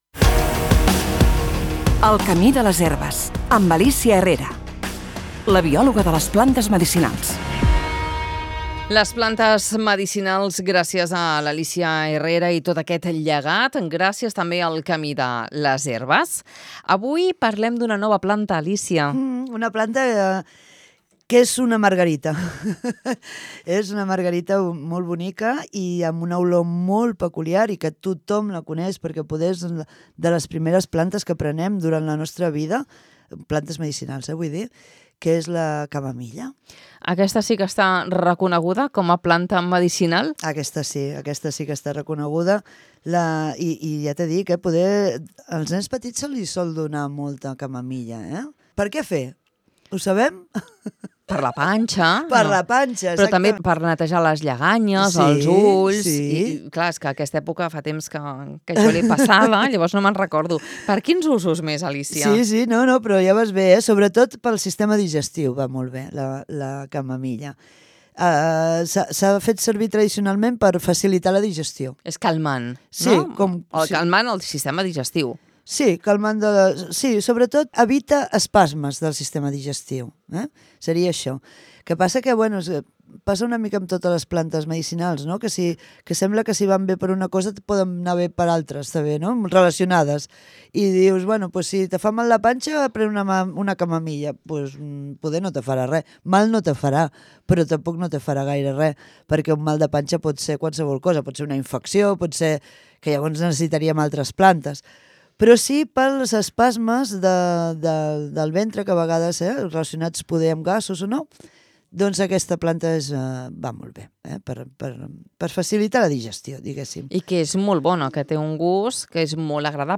A la darrera secció del dia, hem conversat